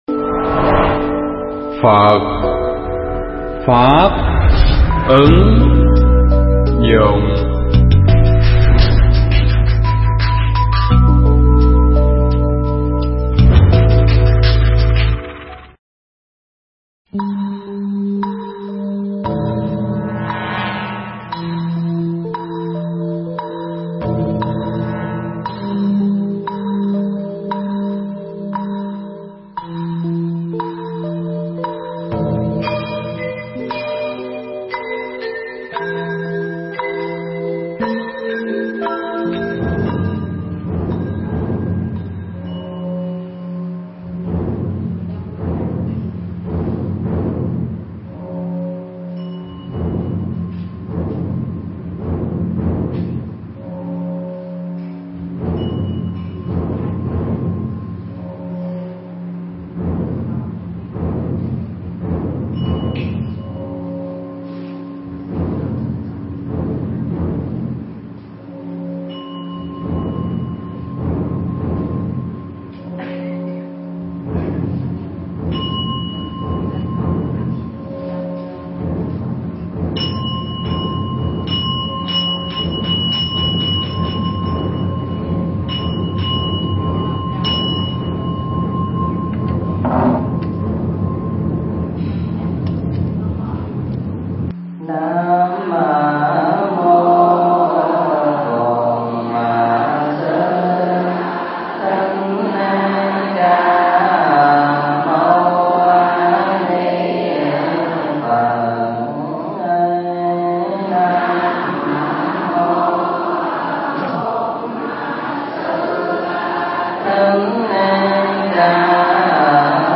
Mp3 Pháp thoại Tình Yêu Hạnh Phúc Và Oan Trái english sub